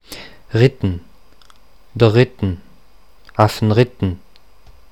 Deutsch-mundartliche Form
[dər ritn]
[afn ritn]
Ritten_Mundart.mp3